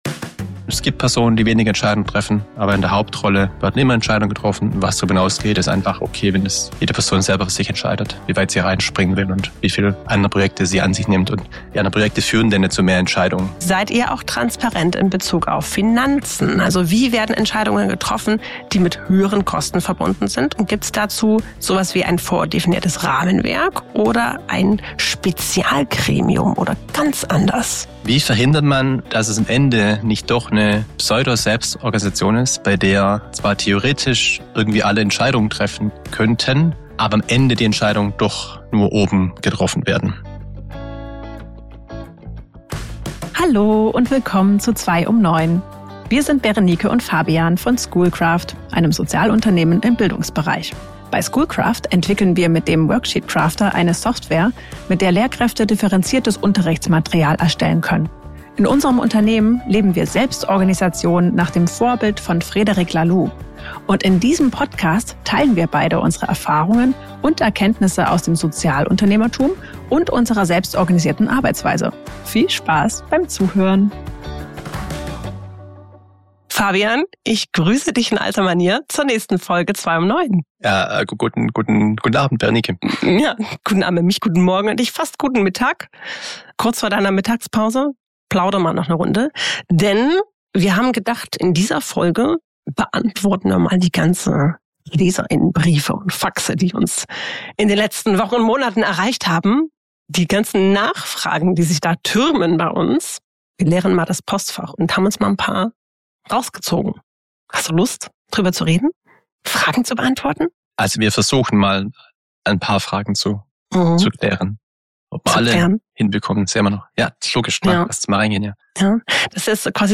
In einem ehrlichen Q&A-Format beantworten wir eure Rückfragen zu unserer Arbeit bei SchoolCraft, zur Selbstorganisation und zu den typischen Stolpersteinen auf dem Weg dahin. Wir sprechen darüber, wie es ist, wenn nicht alle Kolleg*innen gleich viele Entscheidungen treffen wollen – und warum das in einer gut gedachten Selbstorganisation trotzdem funktioniert. Und wir zeigen, wie Verantwortung und Entscheidungsfreiheit in Rollen klar geregelt sind.